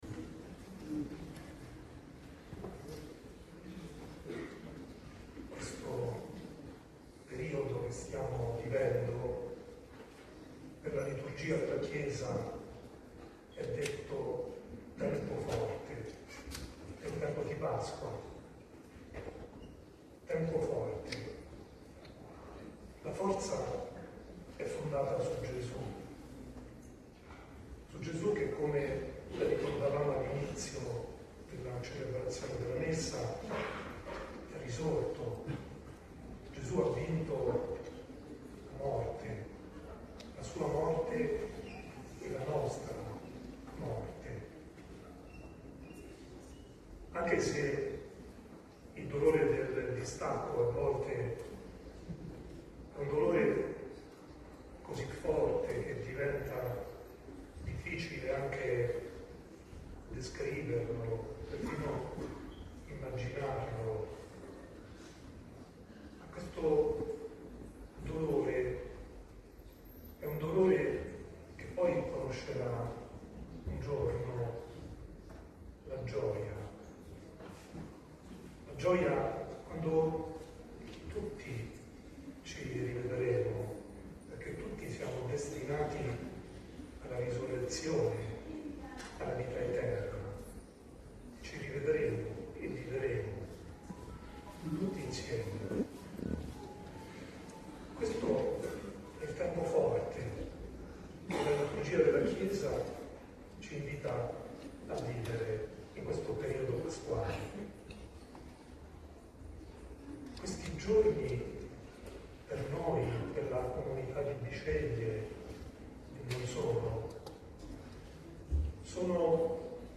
OMELIA DELL’ARCIVESCOVO
TENUTA A BISCEGLIE IL 17 APRILE NELLA CHIESA PARROCCHIALE DI SANTA CATERINA DA SIENA